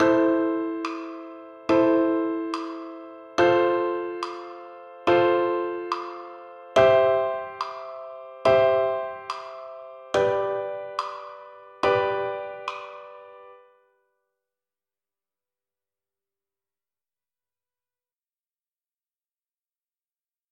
para flauta, y xilófonos.